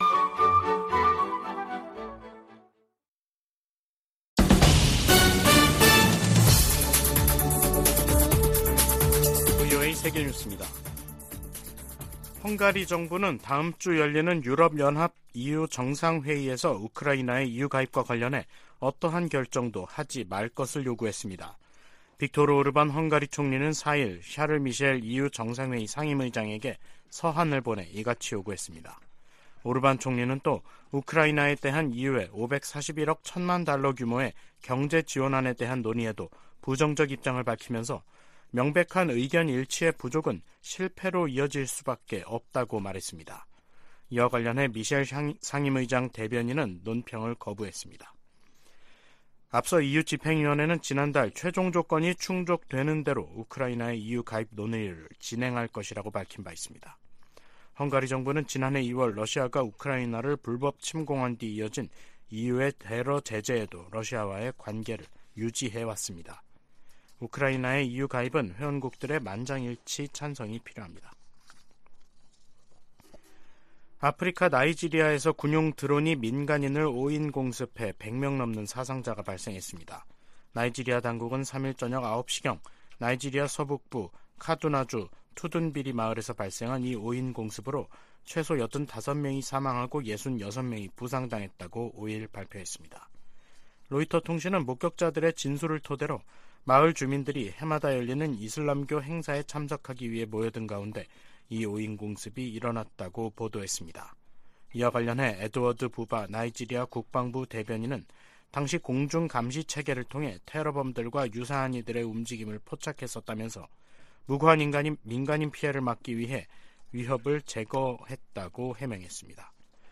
VOA 한국어 간판 뉴스 프로그램 '뉴스 투데이', 2023년 12월 5일 3부 방송입니다. 미국 정부가 남북한의 정찰 위성 발사에 대해 이중 기준을 가지고 있다는 북한의 주장을 일축했습니다. 국제 법학 전문가들도 북한이 정찰위성 발사에 대해 국제법적 정당성을 강변하는 것은 국제 규범 위반이라고 지적했습니다. 미 하원 군사위원회 부위원장이 북한 정찰위성 발사를 강력 규탄했습니다.